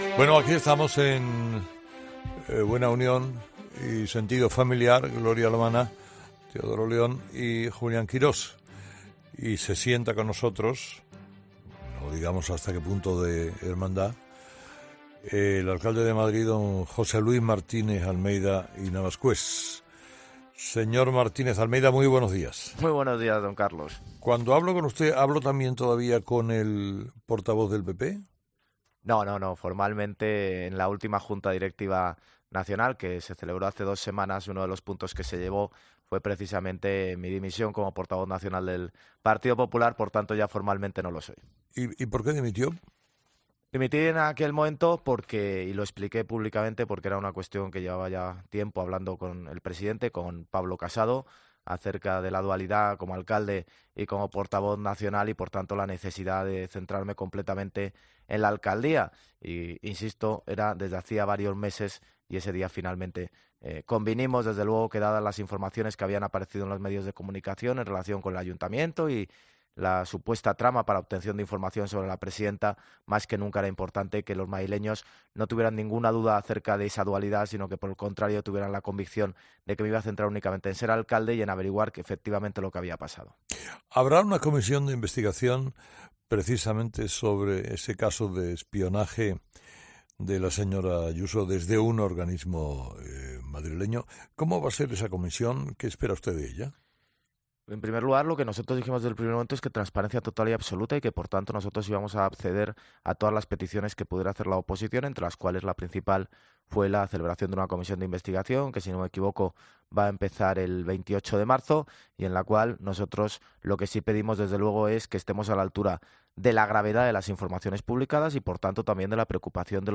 José Luis Martínez-Almeida ha concedido su primera entrevista en COPE, en ‘Herrera en COPE’ una vez que el PP está definiendo su nueva hoja de ruta tras el estallido de la guerra interna entre Pablo Casado e Isabel Díaz Ayuso que acabó salpicando a todo el partido y que se cobró como ‘víctimas’ al propio Casado y al secretario general del partido Teodoro García Egea.